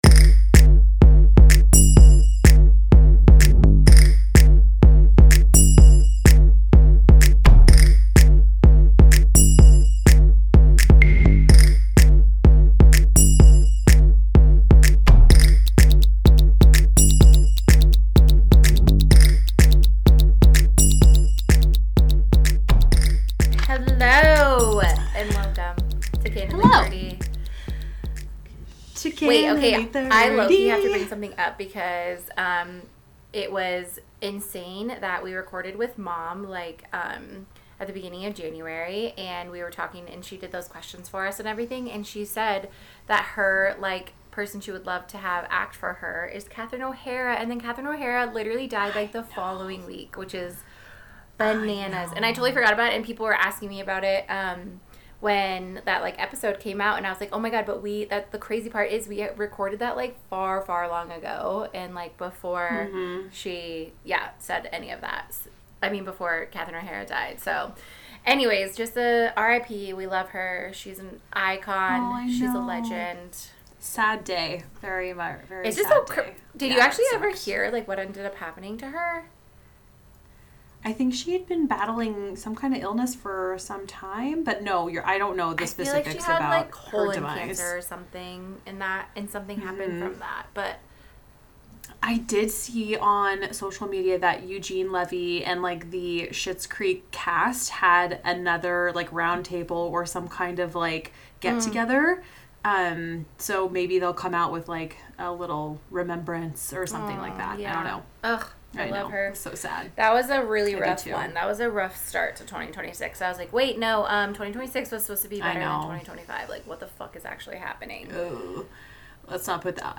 Ladies discuss Olympics, movies and other current events.